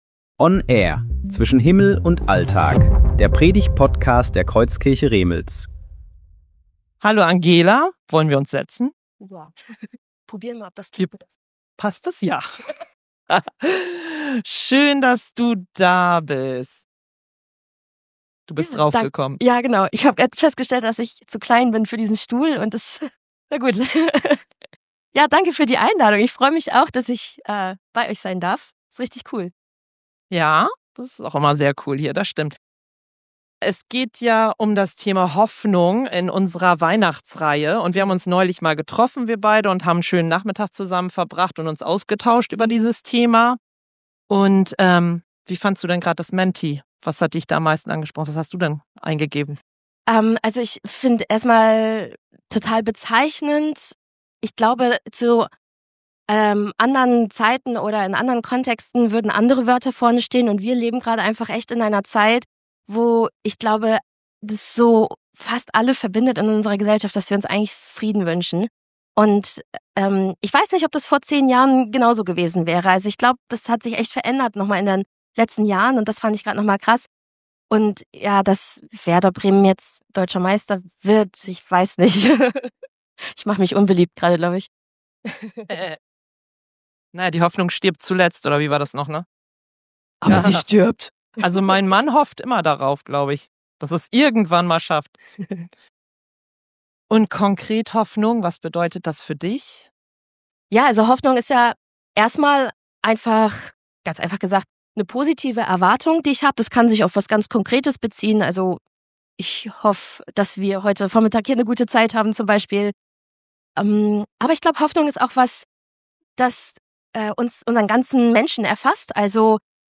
Gottesdienst Datum: 30.11.2025 Bibelstelle: 1. Petrus 1,3 Audio herunterladen